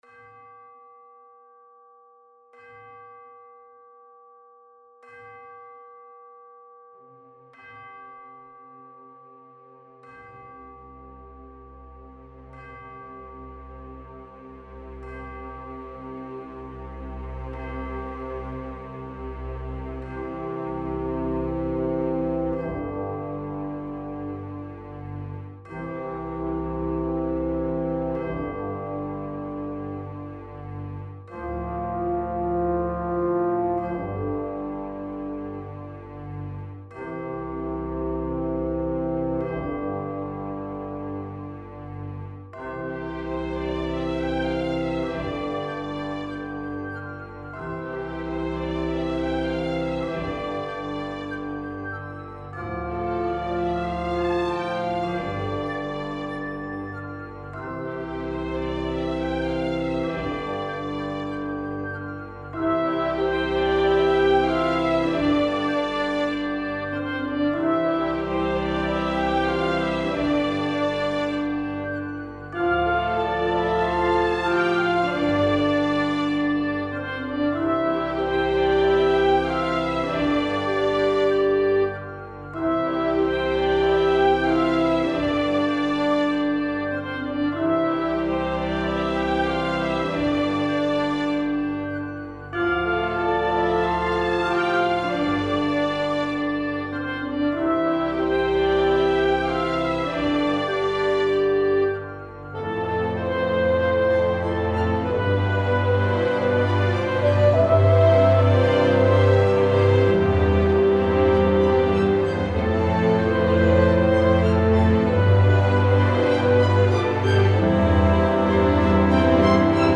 Bells Across the Valley Orchestra, 3:53.
Inspired by the sound of the church bells on a sunny Sunday morning, heard from my room at a shared house when I was at university.